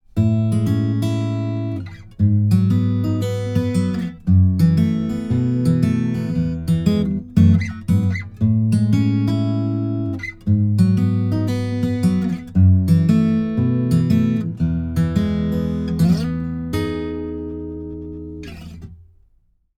ギター：Gibson J45
マイクプリ：SSL XLogic（EQやコンプは不使用）
録音後には一切処理を行わず、そのまま書き出しています。
フィンガーピッキングによるアコースティックギターのレコーディング
LCT 440 PURE サウンドホールを狙ったサンプル
特にこのサンプルのような美しいアルペジオを収録するときには、椅子の軋みやフレットノイズなど多くの「気にするポイント」があることでしょう。